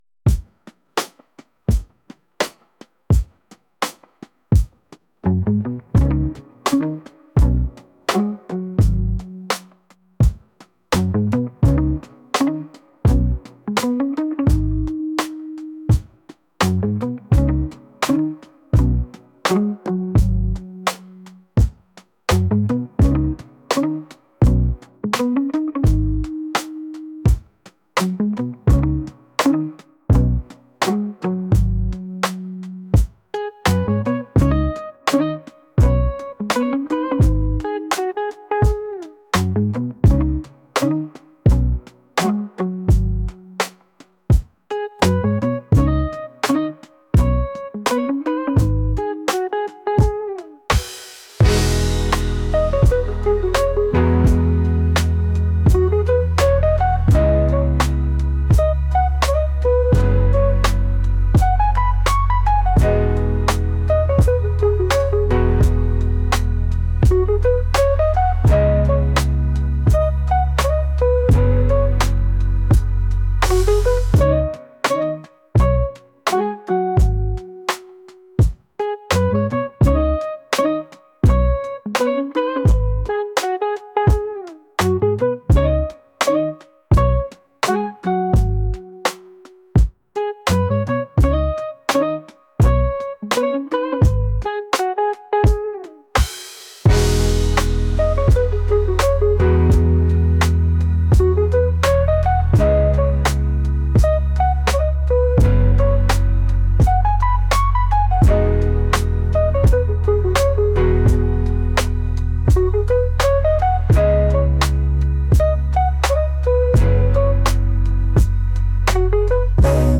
pop | soul & rnb